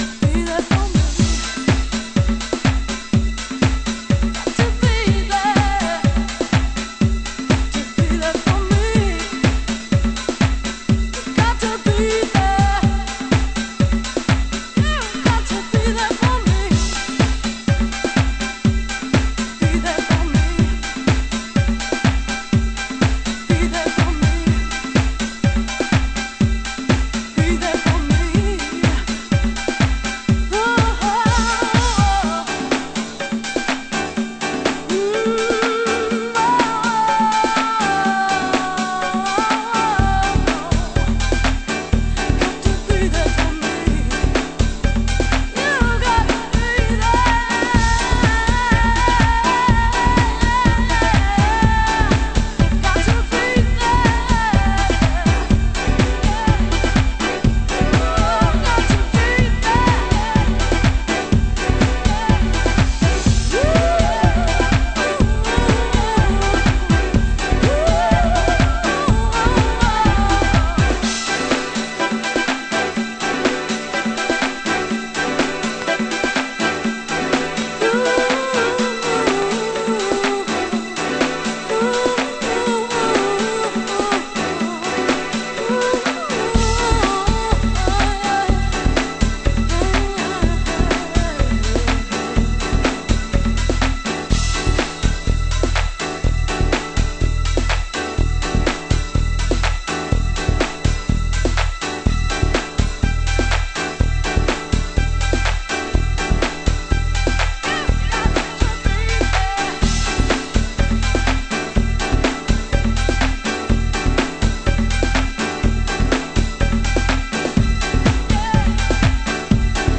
DEEP